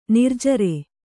♪ nirjare